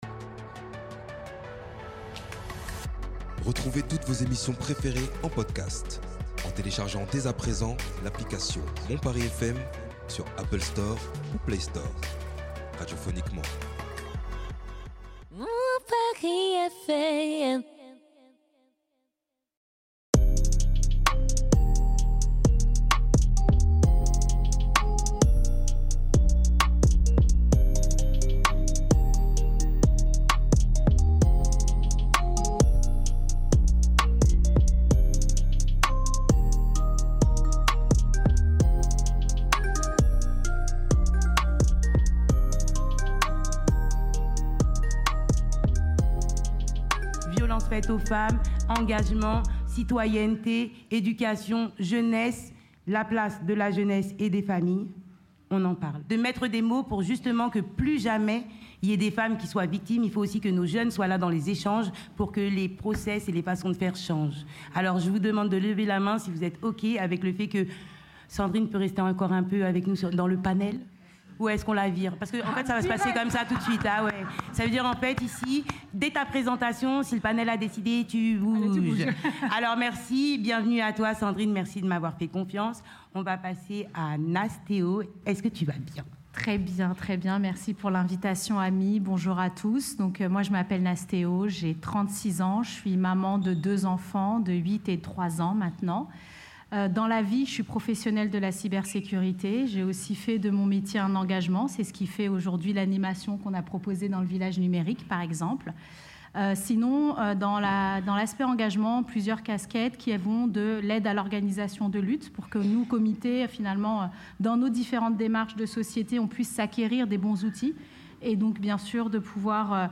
Dans une ambiance chaleureuse et intergénérationnelle, jeunes et moins jeunes ont pris la parole pour discuter de la parentalité sous toutes ses formes. Entre témoignages sincères, conseils bienveillants et éclats de rire, cet épisode capture l’essence même du salon : un espace d’écoute, de partage et de transmission.
En plein cœur de plus de 1000 m² d’exposition, au milieu de stands variés et d’animations pour toute la famille, cette table ronde a réuni des voix inspirantes autour de sujets essentiels… et parfois tabous.
Plongez au cœur de ce moment unique enregistré en live, et (re)découvrez la parentalité comme vous ne l’avez jamais entendue.